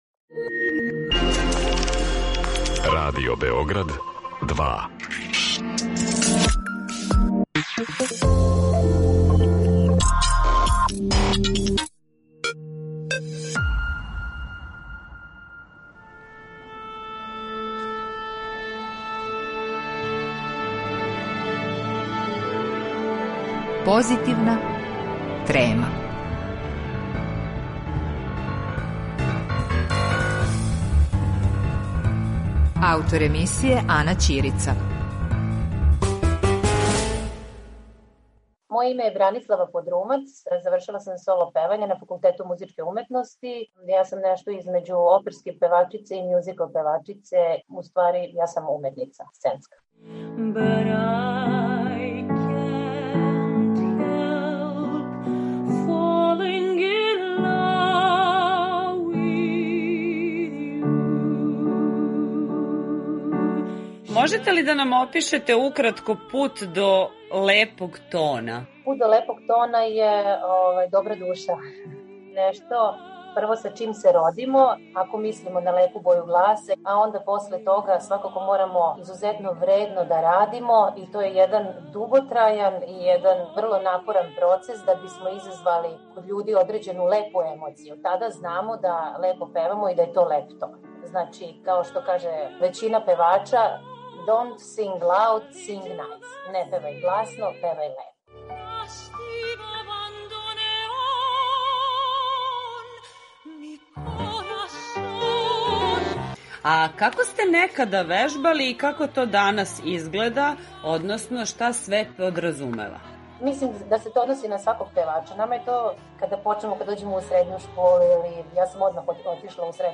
О лепом тону, паметном вежбању и узбуђењу пред наступ, разговарамо са вишеструко надареном уметницом изузетне моћи трансформације, која са подједнаком лакоћом влада сценом у оперским, оперетским, улогама у мјузиклима, па и драмским улогама.